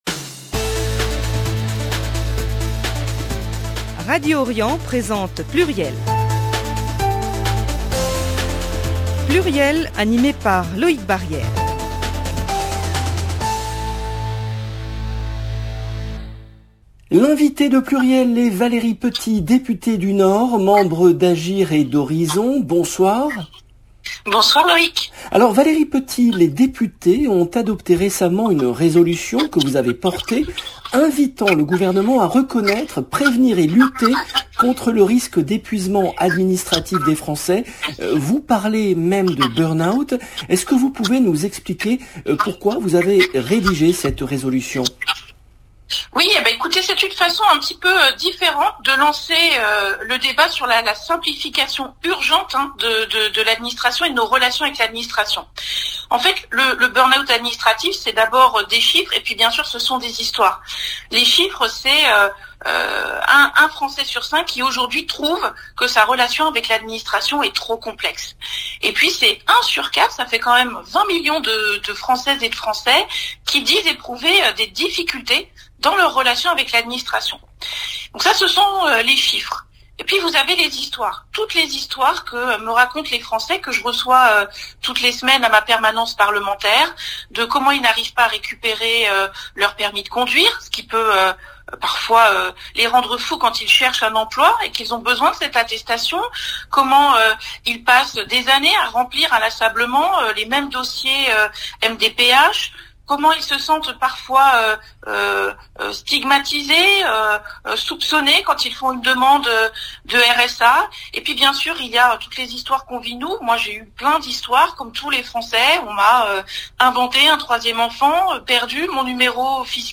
le rendez-vous politique du mardi 21 décembre 2021 L’invitée de PLURIEL est Valérie Petit , députée du Nord, membre d’Agir et d'Horizons.